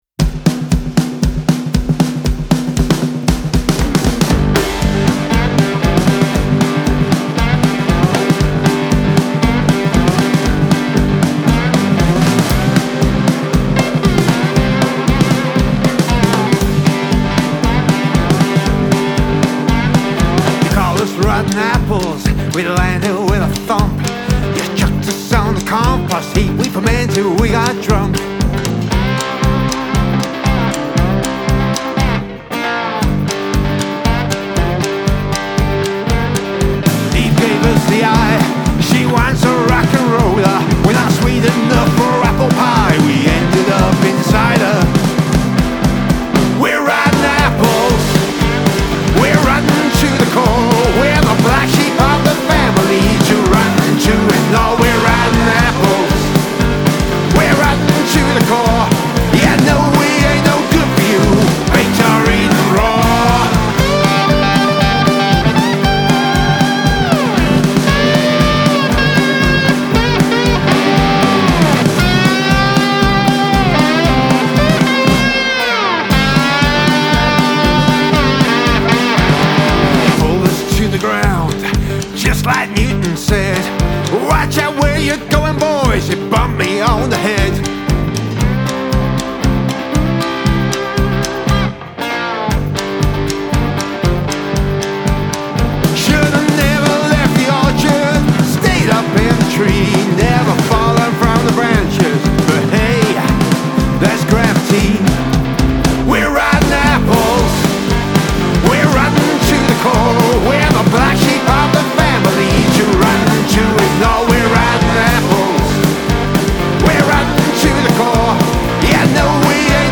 Male Vocal, Guitar, Cigar Box Guitar, Bass Guitar, Drums